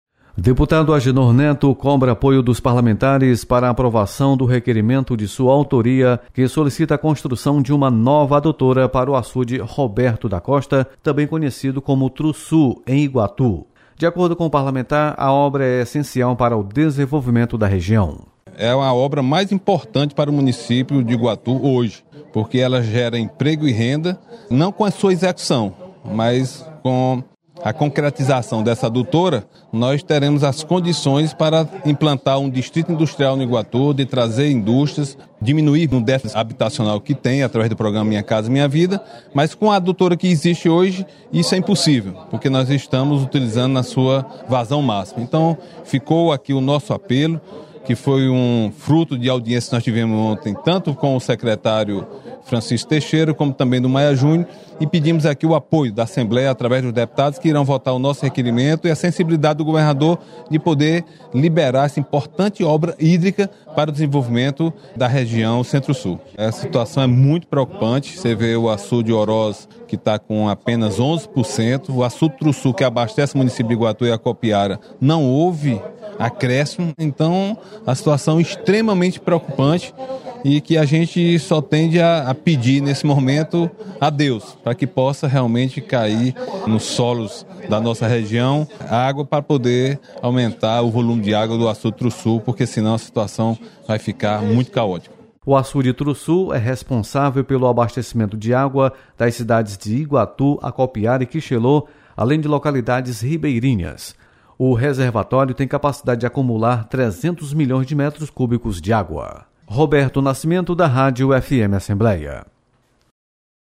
Deputado Agenor Neto  propõe nova adutora para açude Trussu em Iguatu. Repórter